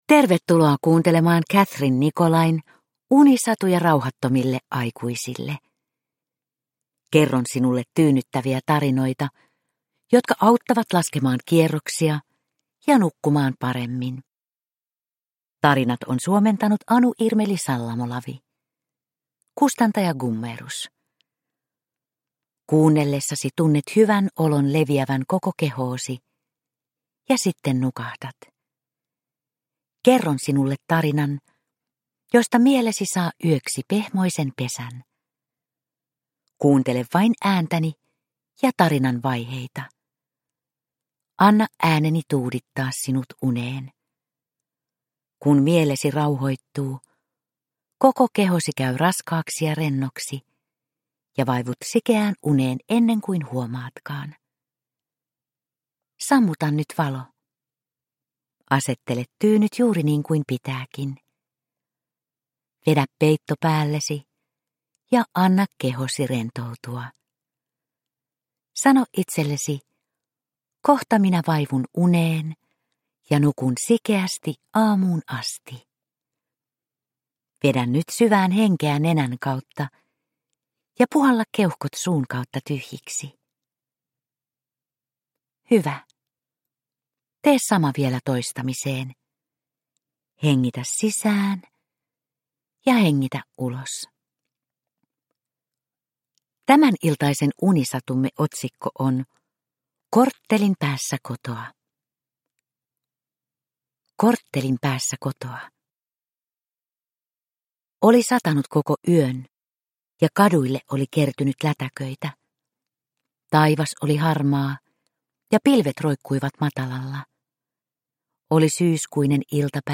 Unisatuja rauhattomille aikuisille 48 - Korttelin päässä kotoa – Ljudbok – Laddas ner